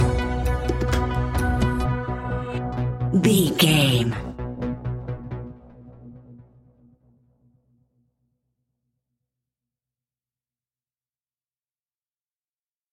In-crescendo
Aeolian/Minor
ominous
eerie
synthesiser
drums
horror music